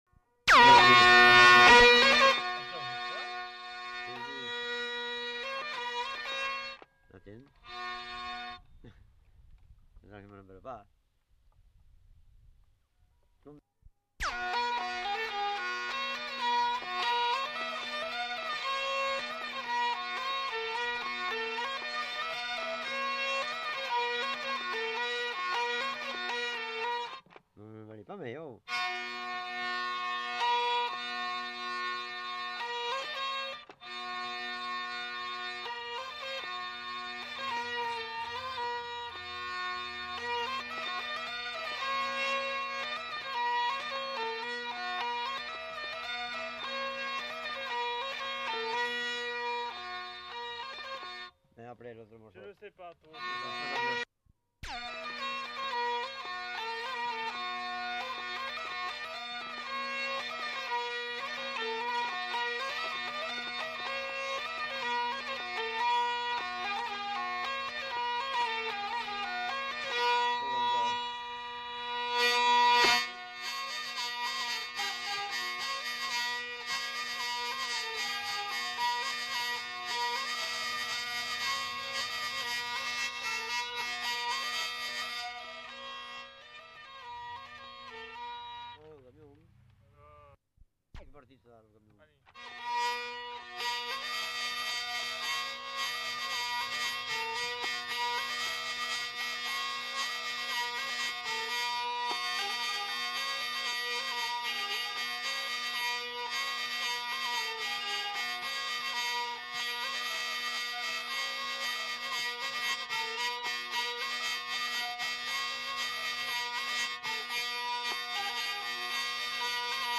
Aire culturelle : Gabardan
Genre : morceau instrumental
Instrument de musique : vielle à roue
Danse : rondeau